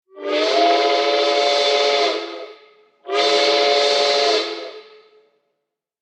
Steam-train-whistle-blowing.mp3